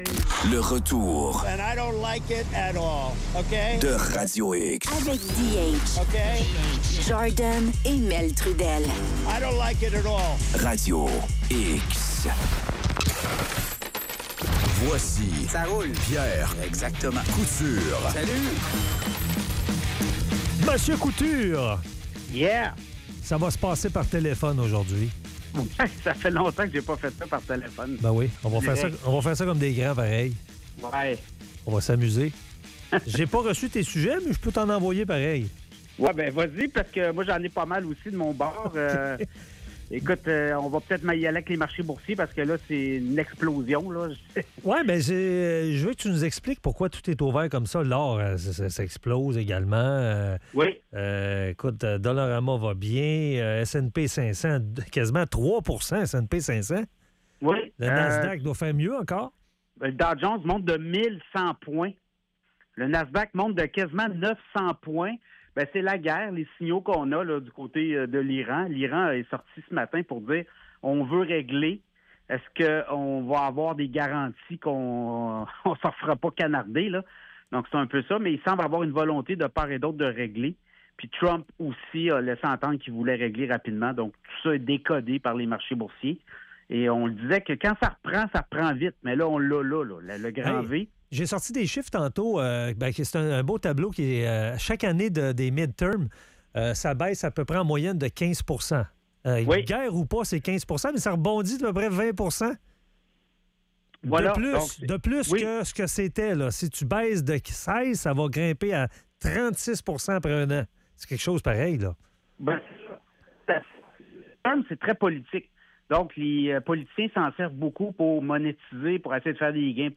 La chronique